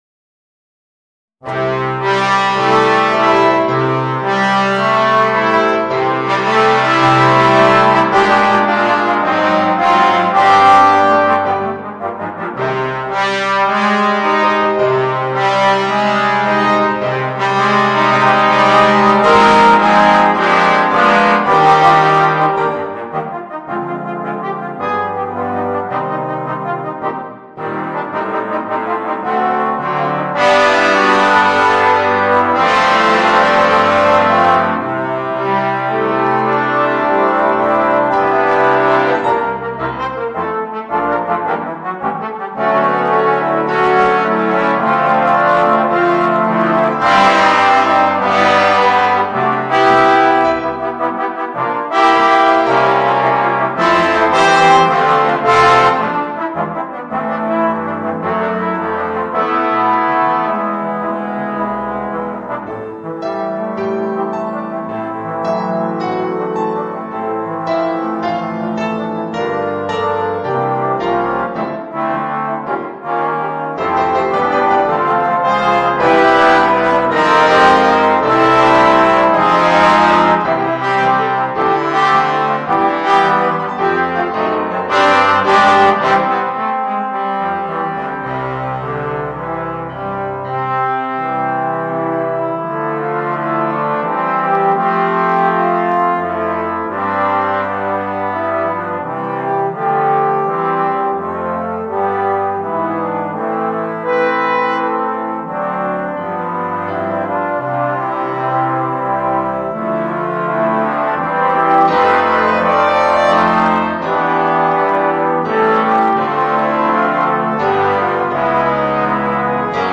トロンボーン八重奏+ピアノ